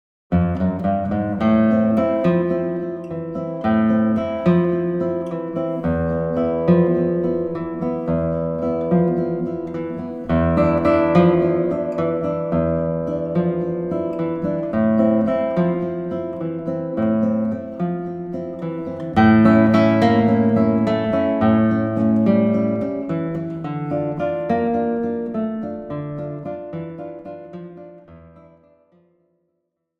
Besetzung: Gitarre
a-Moll